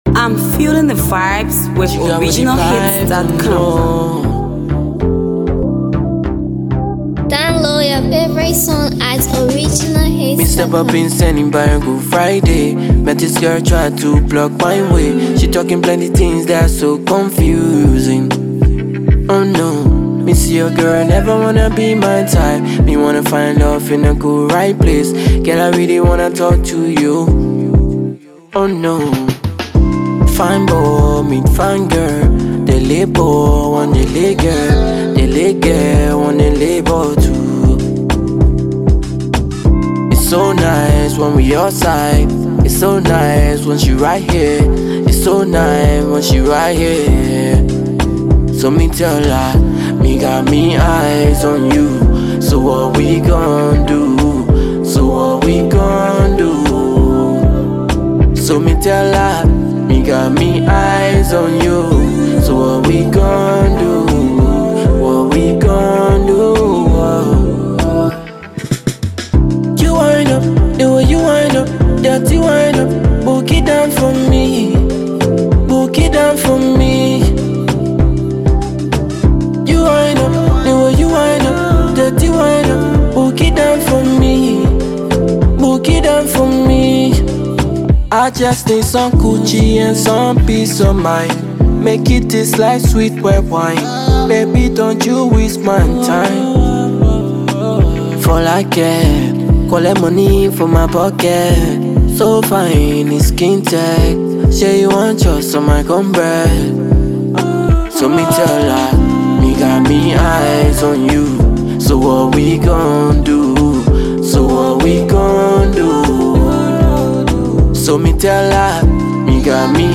is a cool, calm, and collected tune